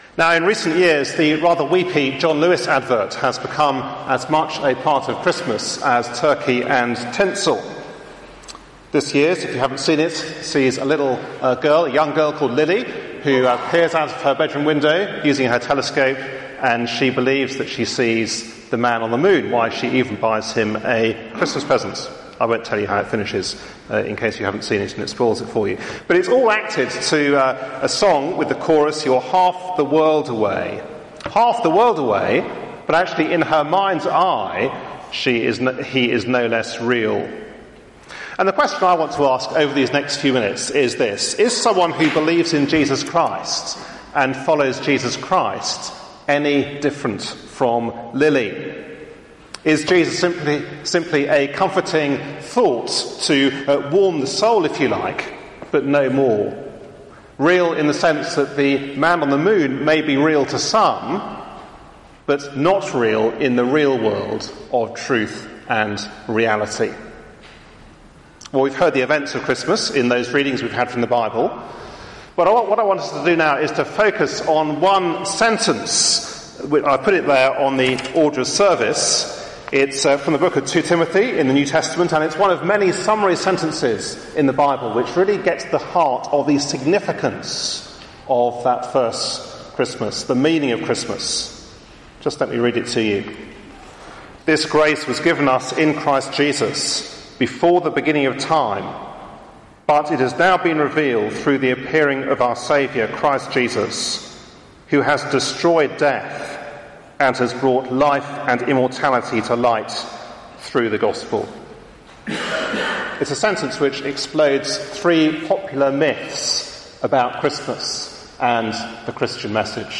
Christmas Carol Service 2015 RECORDED AT THE ALDERSGATE TALKS/ 2 Timothy 1vv9-10 ()
given at a Wednesday meeting